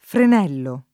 frenello [ fren $ llo ] s. m.